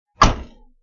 PlayerInteractions » thump 1
描述：Thump
声道立体声